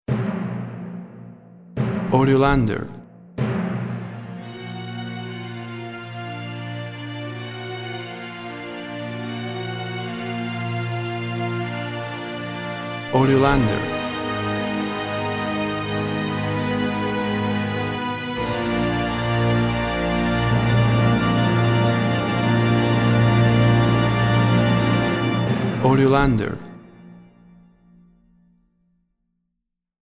Tempo (BPM) 48